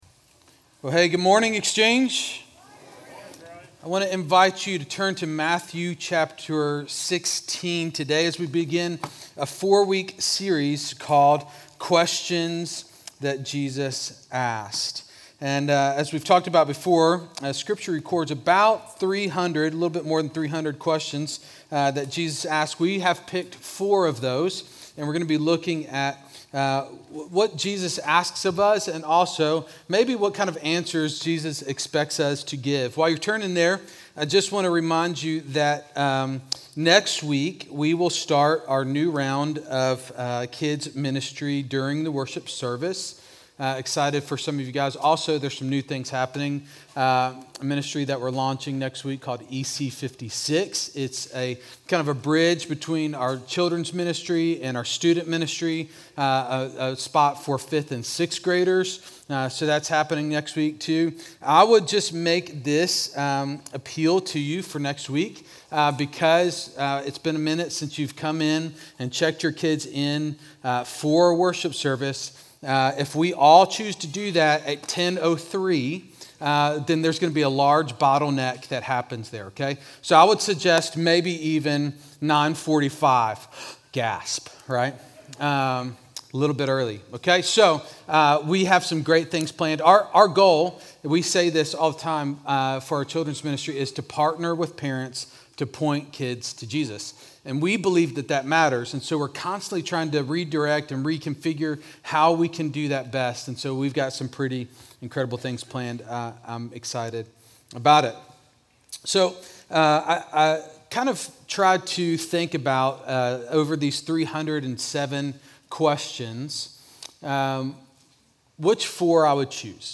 Exchange Church Sermons Who Do You Say That I Am?